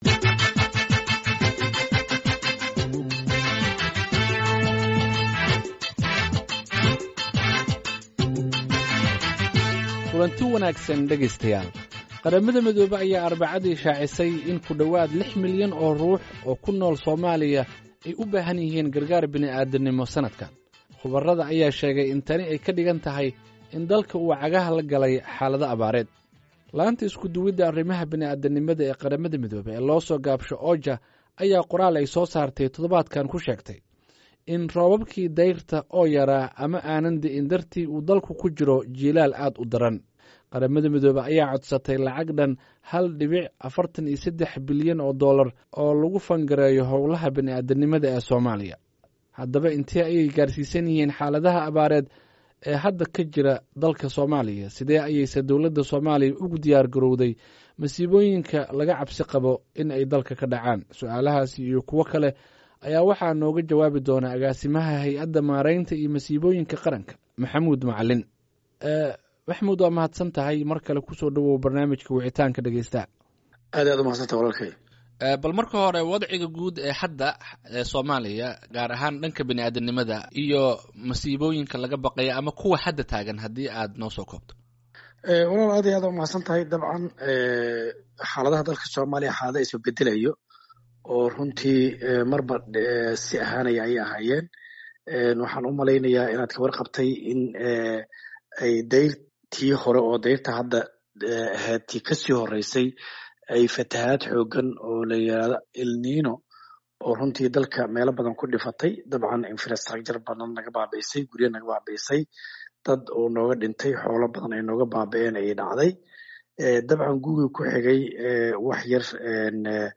Barnaamijka Wicintaanka Dhageystaha waxa uu toddobaadkan ku saabsan yahay; 6 milyan oo qof oo ku nool Soomaaliya oo QM ay sheegtay in ay u baahan yihiin gargaar. Waxaa marti ku ah agaasimaha hay’adda maareynta musiiboyinka qaranka Maxamuud Macallim Cabdulle.